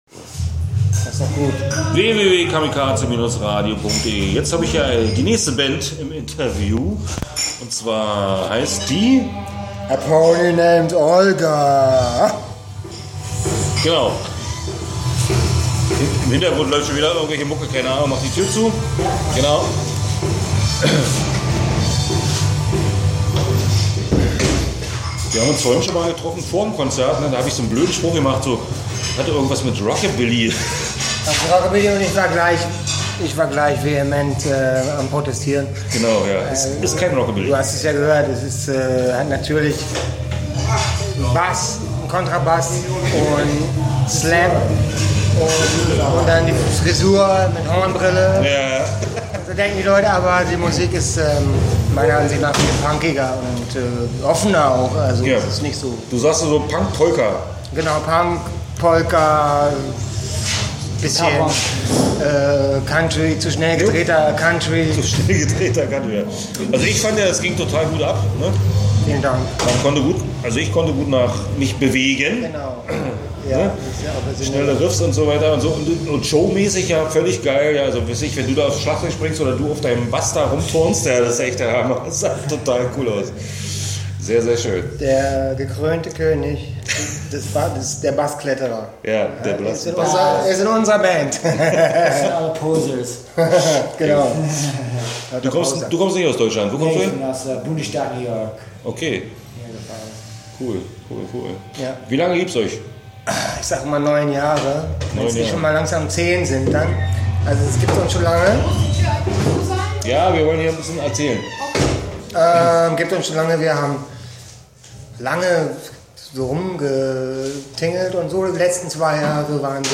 Interview auf der Sommerschlacht 2014